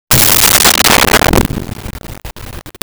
Lofi Bass Drum Hit 02
LoFi Bass Drum Hit 02.wav